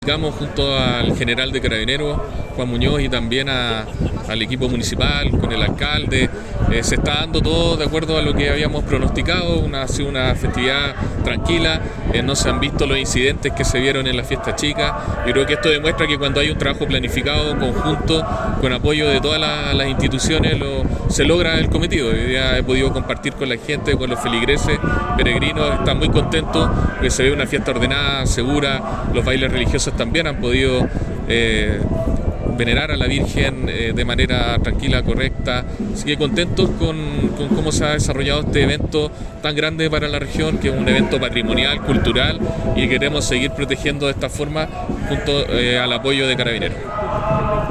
En ese sentido, durante el último día de festividad, el Delegado Presidencial Galo Luna visitó la comuna minera para conocer el trabajo de Carabineros y constatar las medidas implementadas.
FIESTA-GRANDE-Delegado-Presidencial-Galo-Luna.mp3